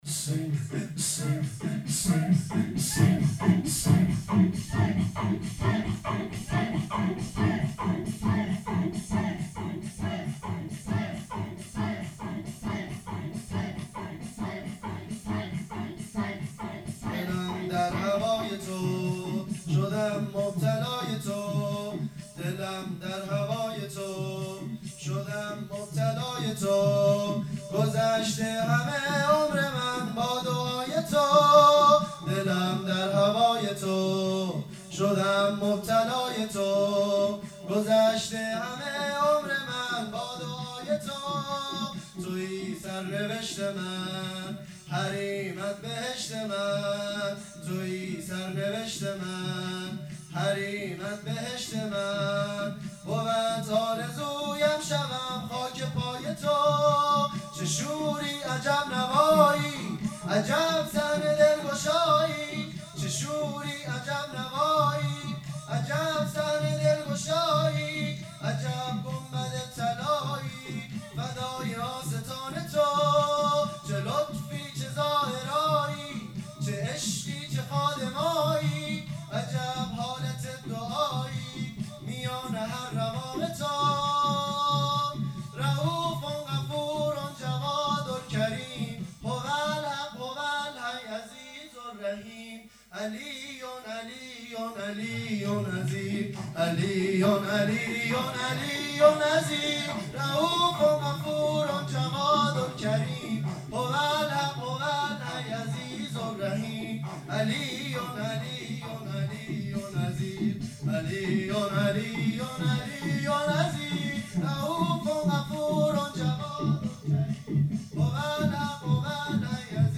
شب دوم | مسجد امام موسی بن جعفر علیه السلام | صفر 1440 |هیئت نوجوانان مکتب الرضا علیه السلام | هیئت جوانان لواء الرقیه سلام الله علیها
ویژه برنامه آخر ماه صفر